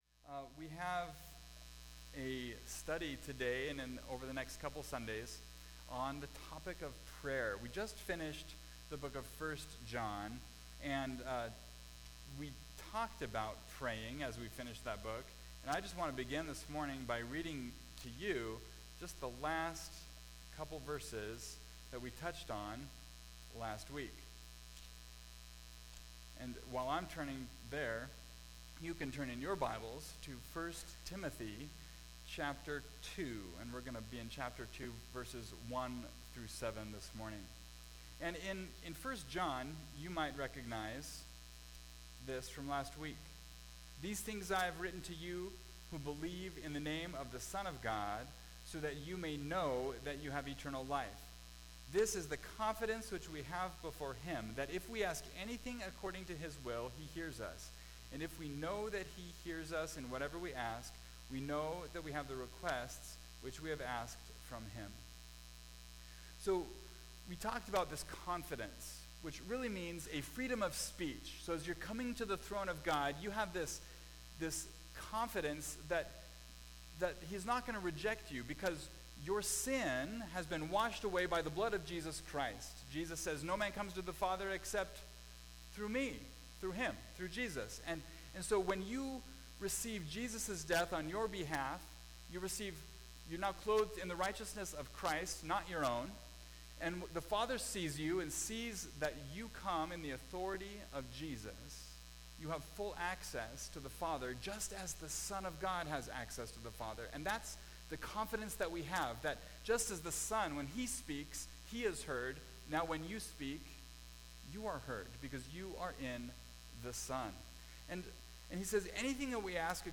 Topical Message